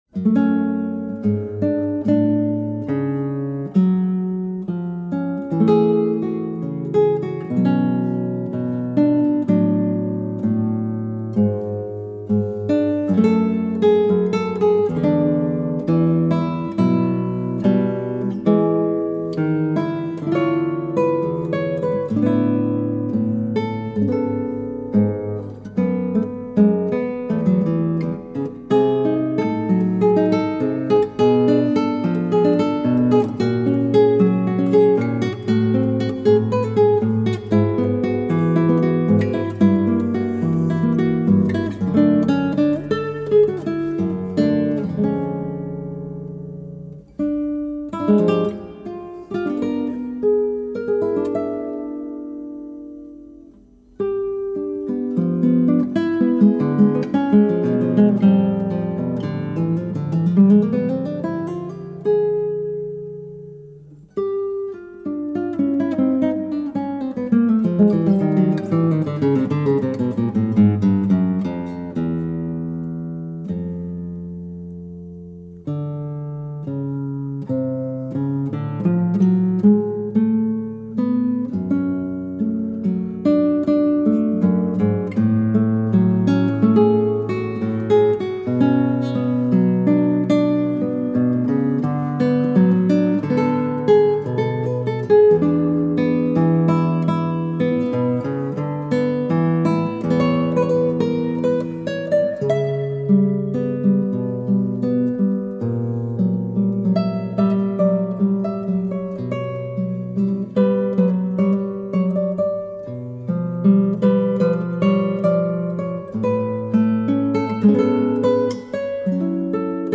Recording for cd-project, Uppsala 2010.